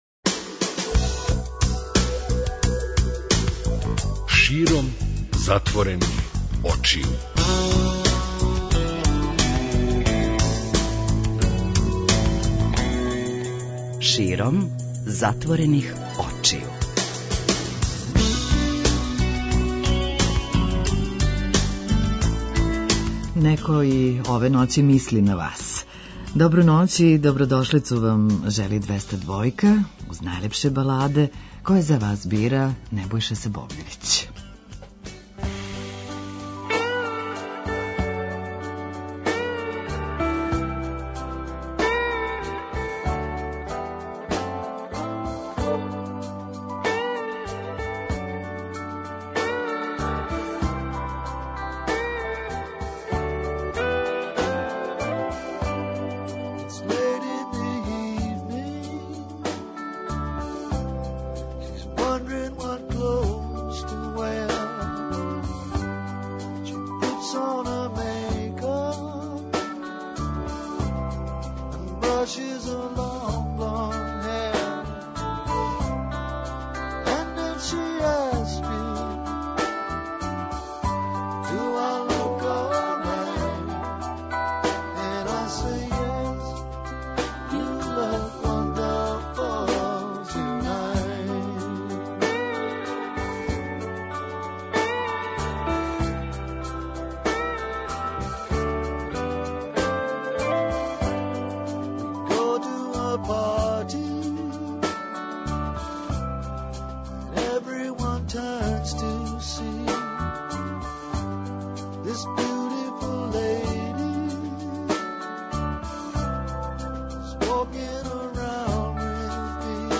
Најлепше баладе на Двестадвојци